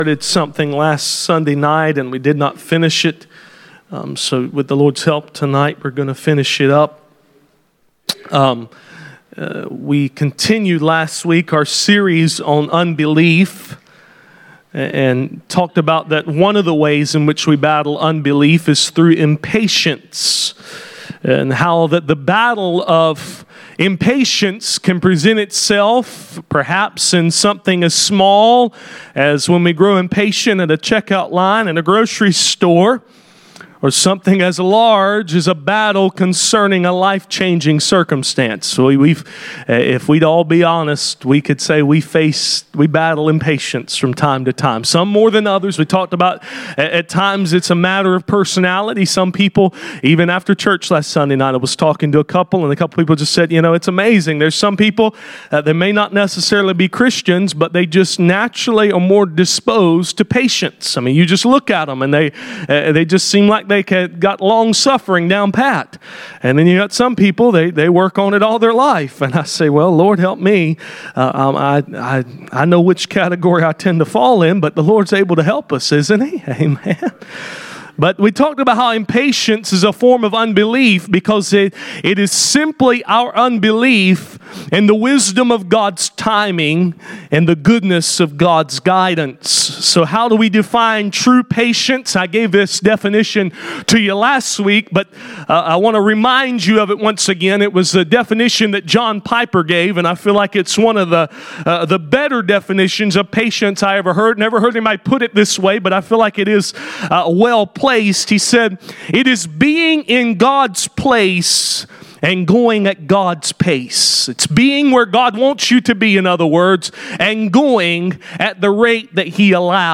James 5:7-11 Service Type: Sunday Evening %todo_render% « Out of many